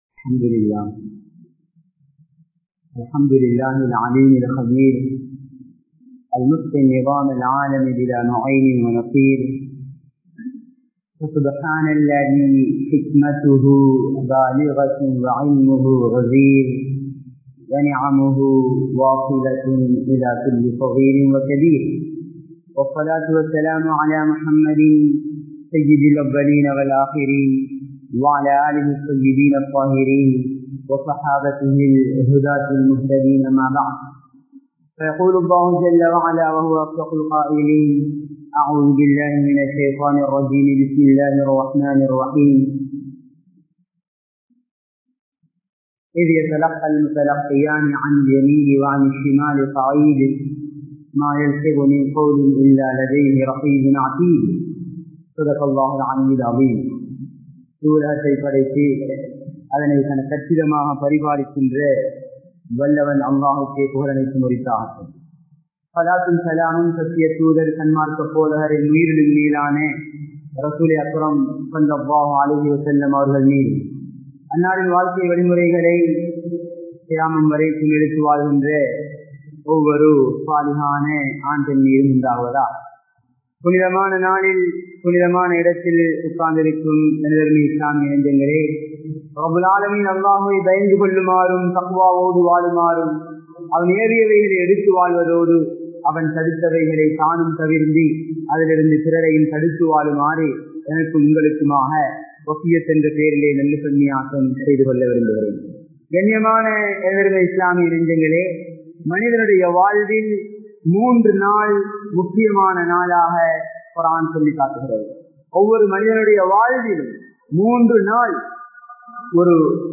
Naavai Peanungal (நாவை பேணுங்கள்) | Audio Bayans | All Ceylon Muslim Youth Community | Addalaichenai
Panadura, Pallimulla Jumua Masjith